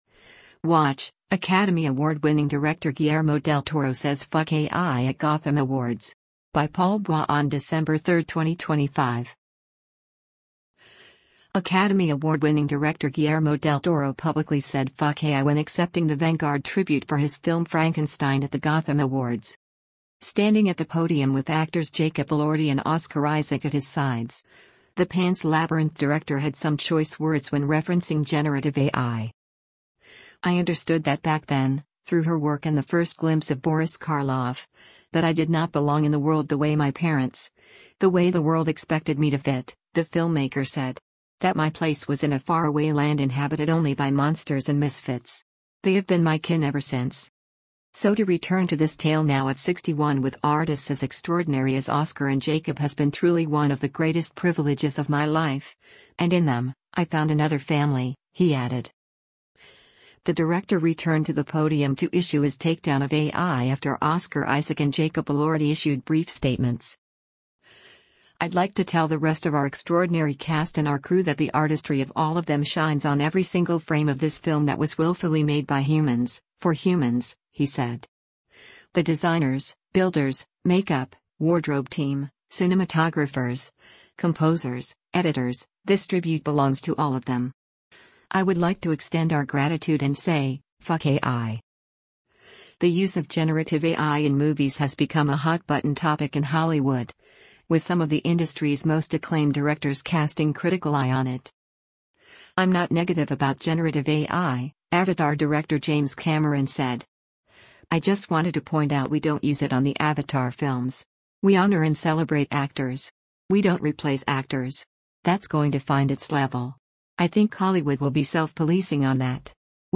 Academy Award-winning director Guillermo del Toro publicly said “fuck AI” when accepting the Vanguard Tribute for his film Frankenstein at the Gotham Awards.
The director returned to the podium to issue his takedown of AI after Oscar Isaac and Jacob Elordi issued brief statements.